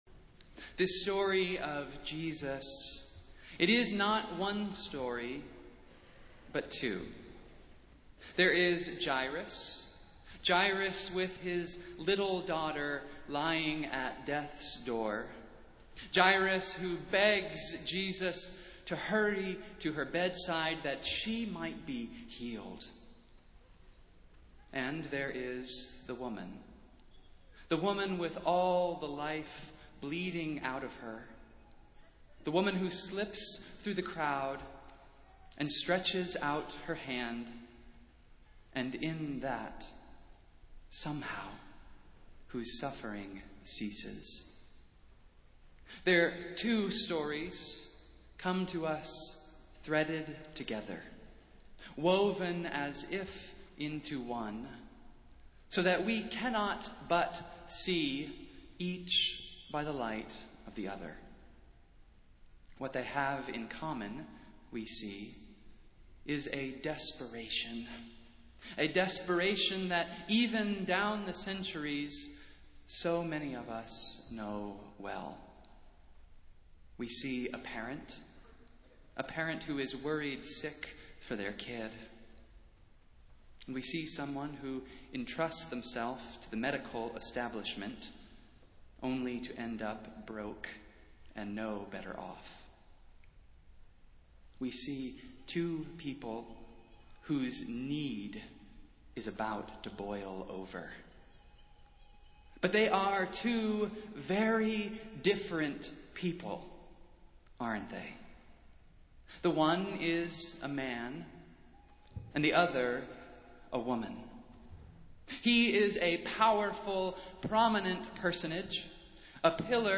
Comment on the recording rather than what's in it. Festival Worship - Fifth Sunday after Pentecost